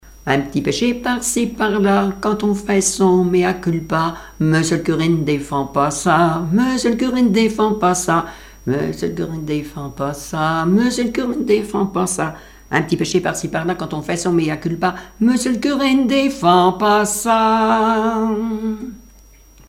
Enfantines - rondes et jeux
Pièce musicale inédite